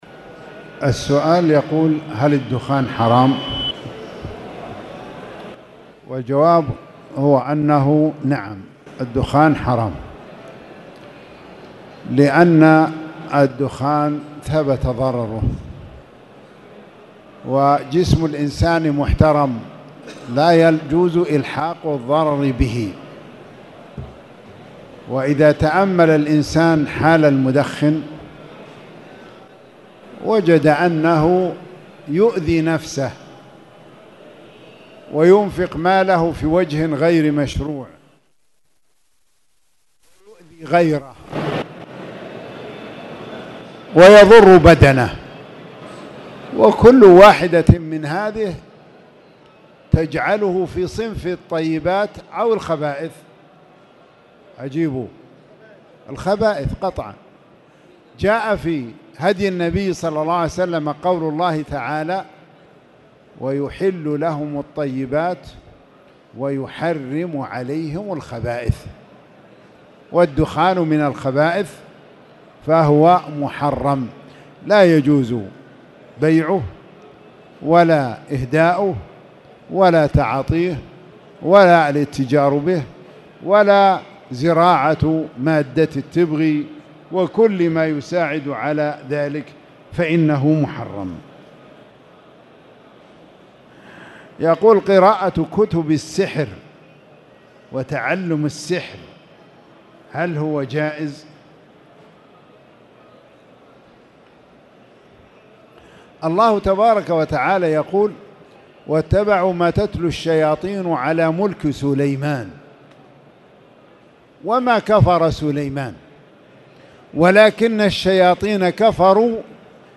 تاريخ النشر ٢٠ ربيع الأول ١٤٣٨ هـ المكان: المسجد الحرام الشيخ